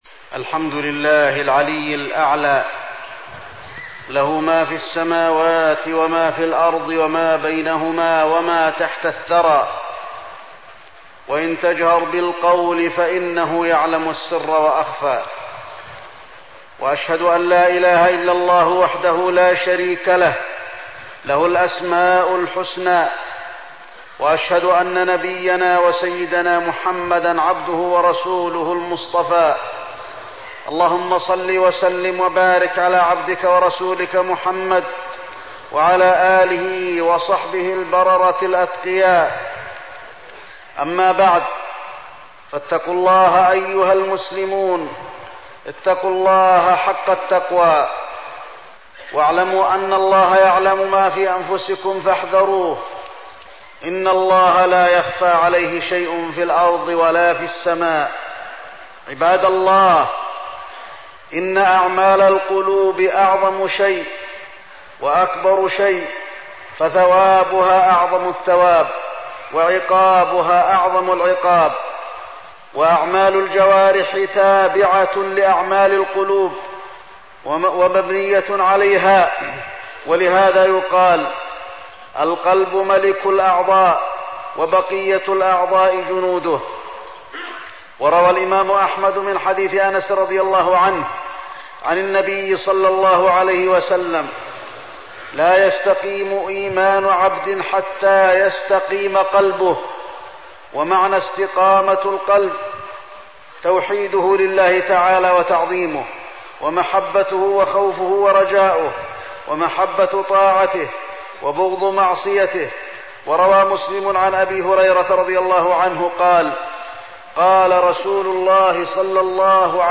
تاريخ النشر ٨ جمادى الأولى ١٤١٧ هـ المكان: المسجد النبوي الشيخ: فضيلة الشيخ د. علي بن عبدالرحمن الحذيفي فضيلة الشيخ د. علي بن عبدالرحمن الحذيفي الخوف من الله